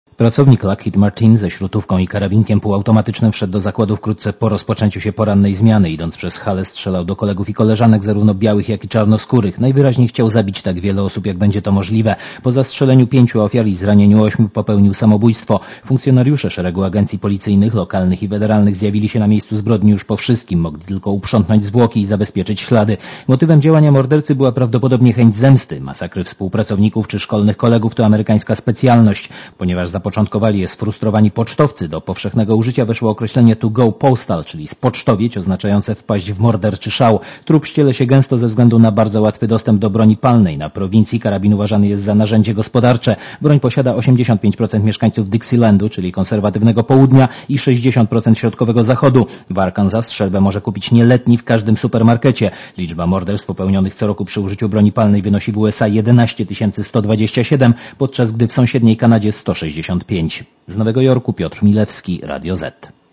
Posłuchaj relacji korespondenta Radia Zet z Nowego Jorku (245 KB)